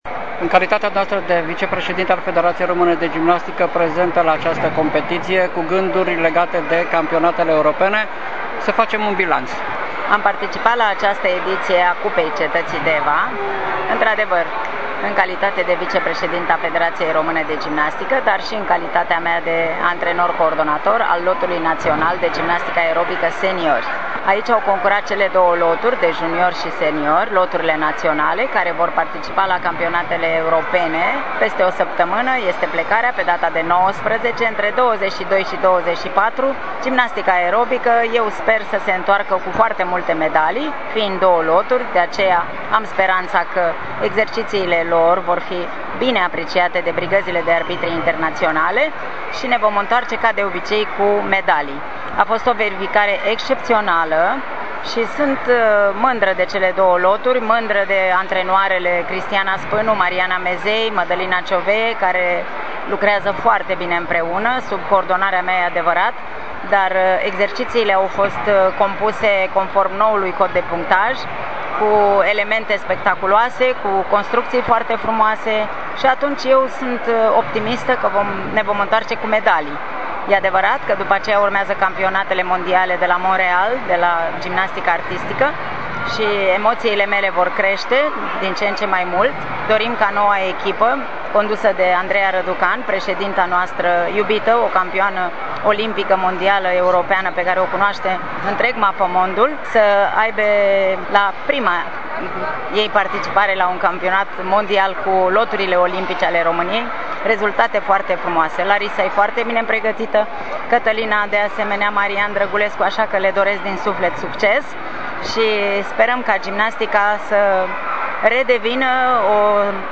Un reportaj